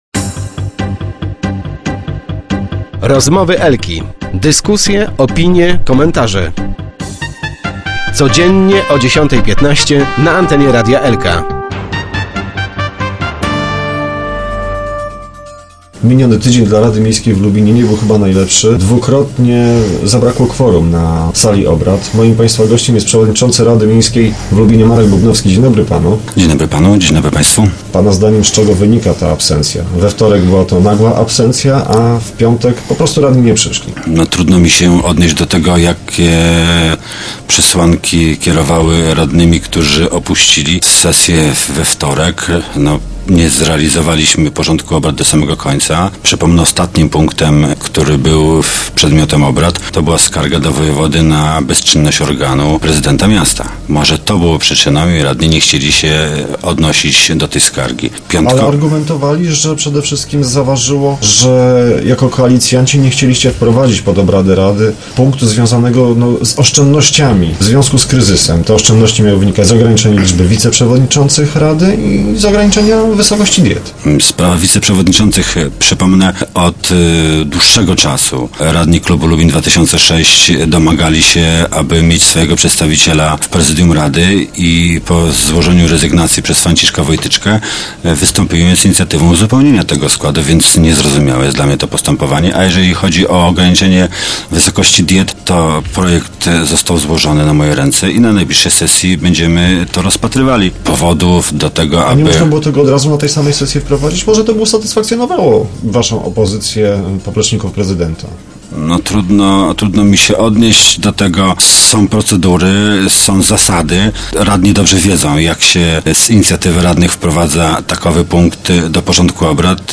Z przewodniczącym rady Markiem Bubnowskim rozmawiał